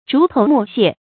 注音：ㄓㄨˊ ㄊㄡˊ ㄇㄨˋ ㄒㄧㄝ ˋ
竹頭木屑的讀法